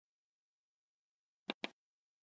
دانلود صدای فشار دادن دکمه از ساعد نیوز با لینک مستقیم و کیفیت بالا
جلوه های صوتی